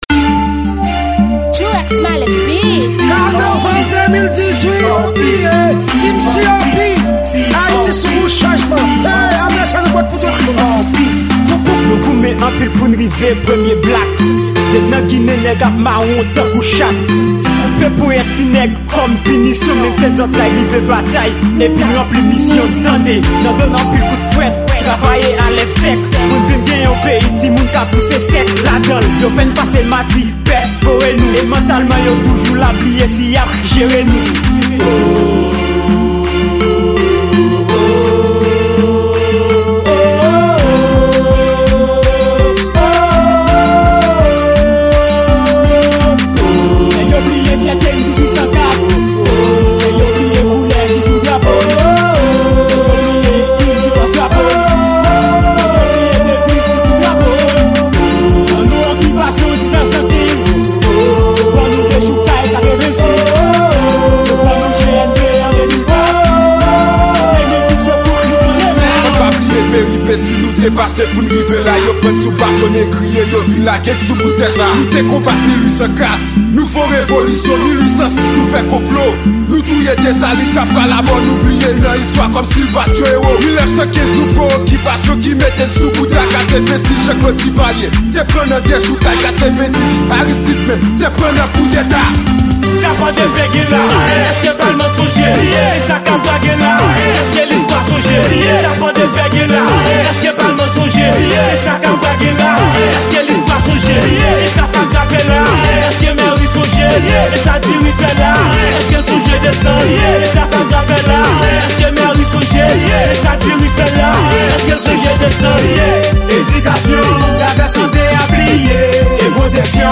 Genre&: Kanaval